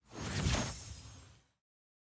UI_BoneTablet_Vanish.ogg